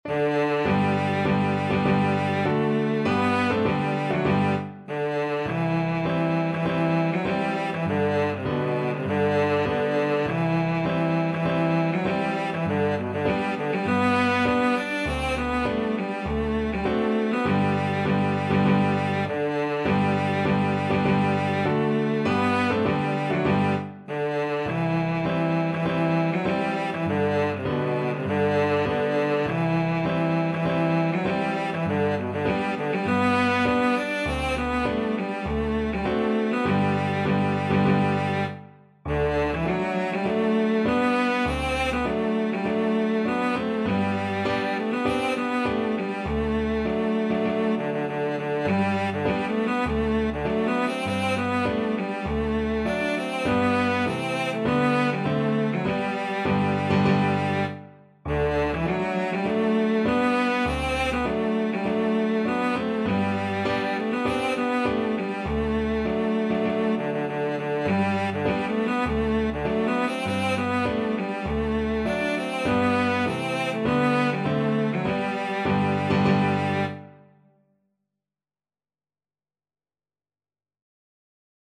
Cello
B3-D5
G major (Sounding Pitch) (View more G major Music for Cello )
Classical (View more Classical Cello Music)